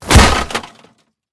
metal2.ogg